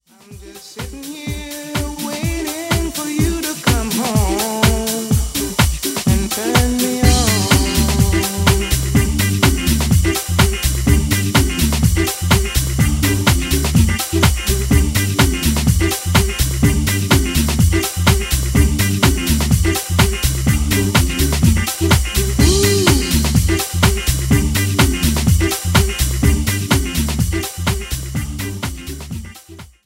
uplifting summertime groover
staccato guitars